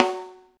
high rim f.wav